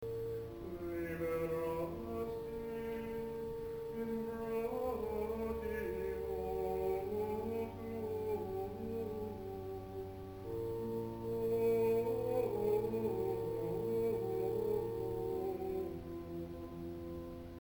Tags: Gregorian Chants Gregorian sounds